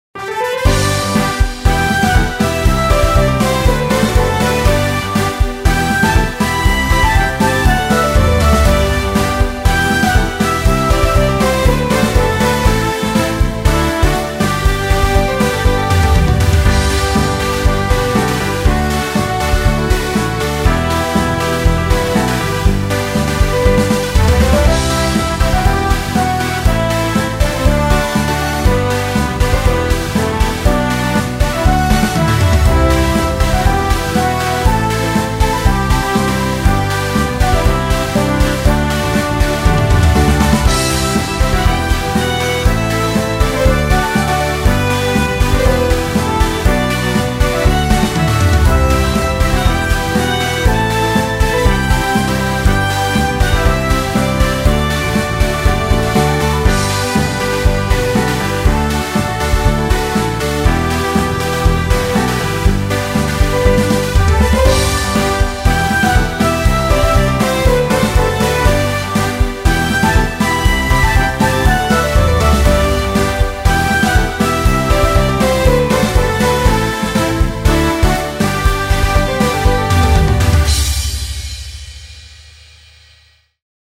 明るい雰囲気の楽曲です。